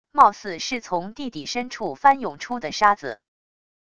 貌似是从地底深处翻涌出的沙子wav音频